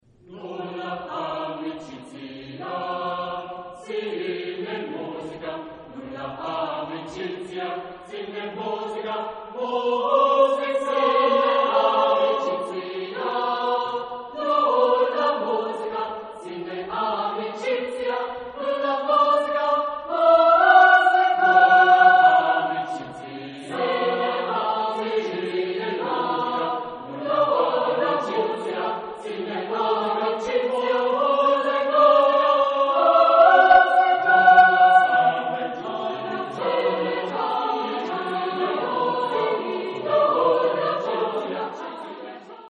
Genre-Style-Forme : Canon ; contemporain ; Profane
Type de choeur : SATB  (4 voix mixtes )
Tonalité : la bémol majeur